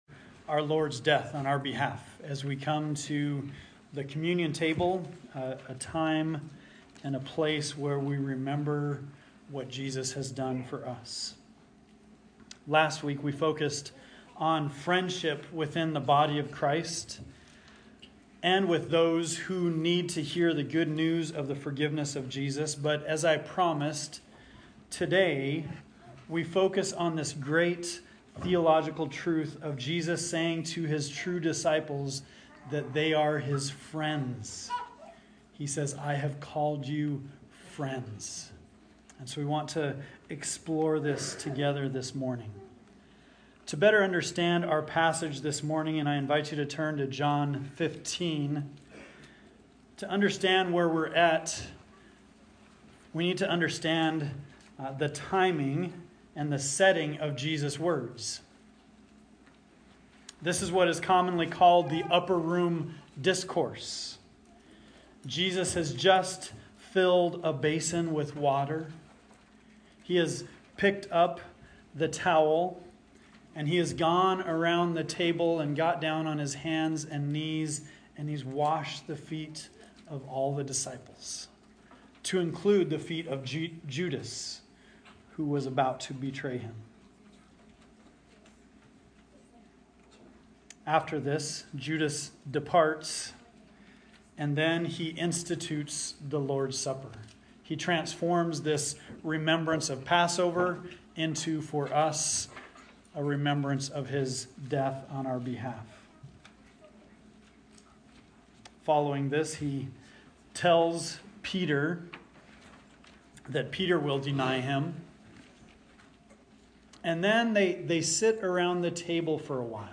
Communion Meditation Passage